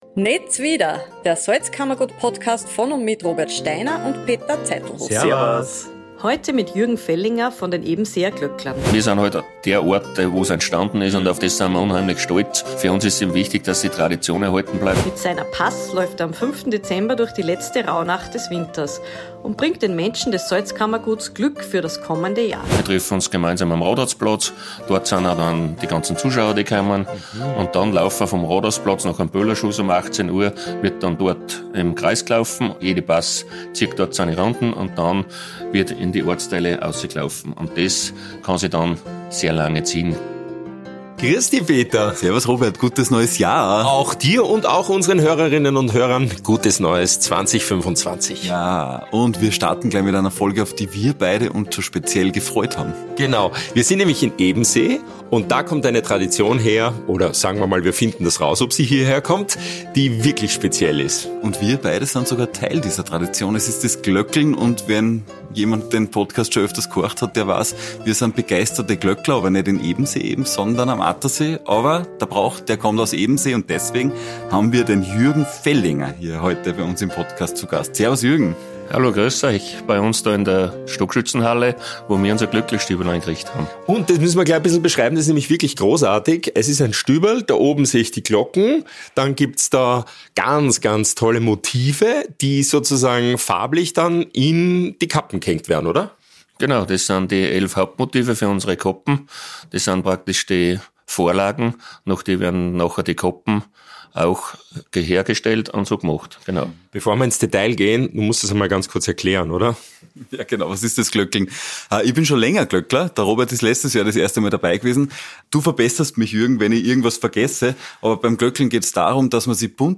Ein lustiges Gespräch über Tradition, Brauchtum und das Leben im Salzkammergut